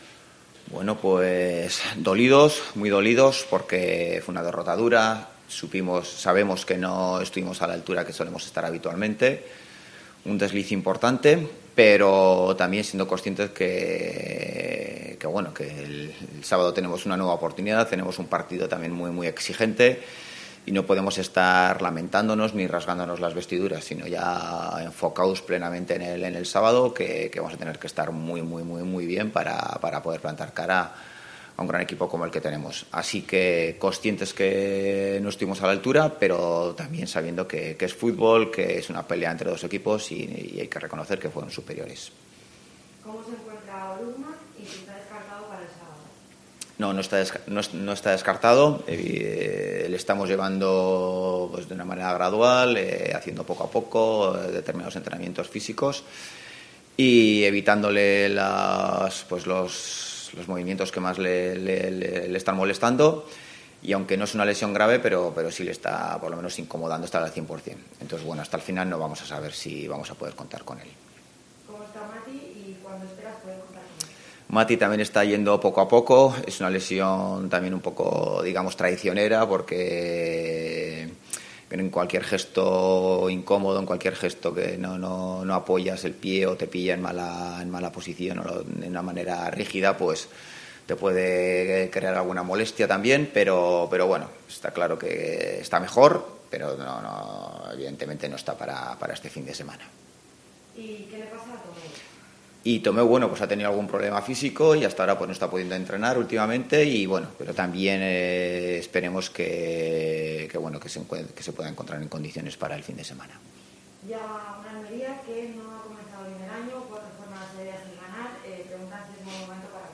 Rueda de prensa Ziganda (Oviedo-Almería)